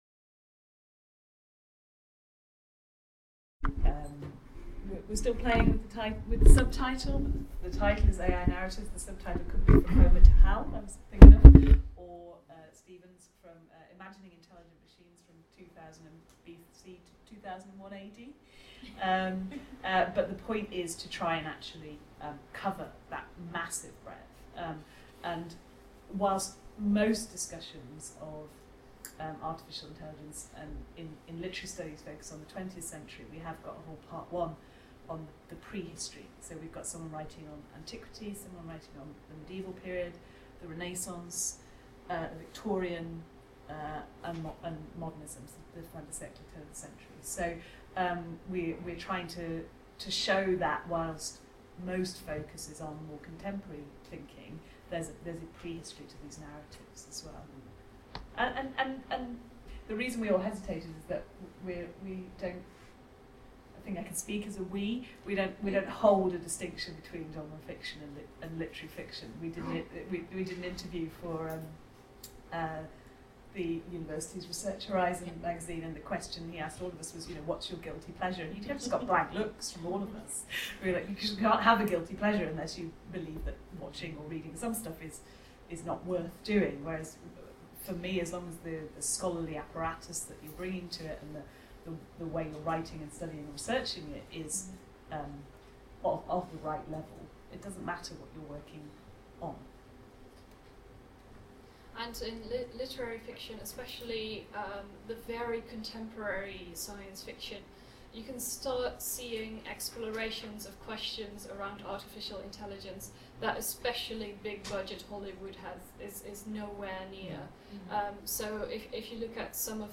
Each session will be organized around two short but very different presentations, followed by a discussion.